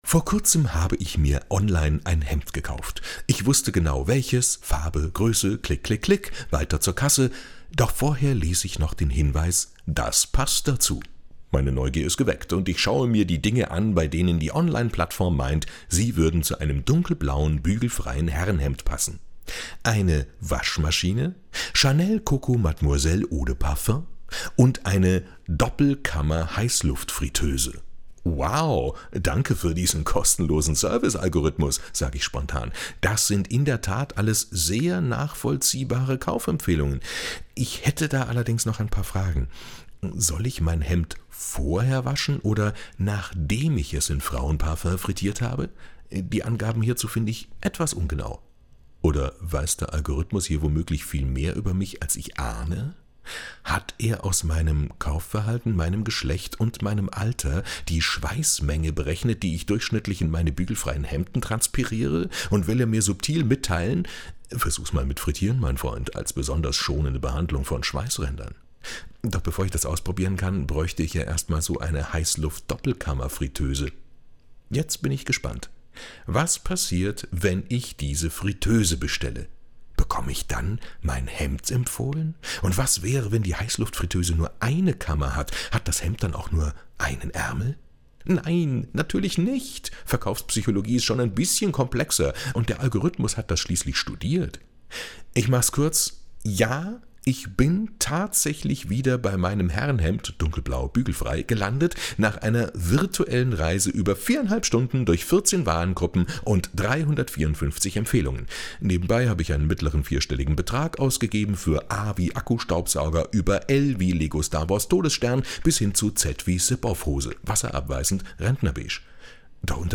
tägliche Glosse von wechselnden Autor*innen, Bayern 2 Radiowelt